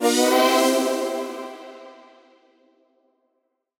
FR_ZString[up]-C.wav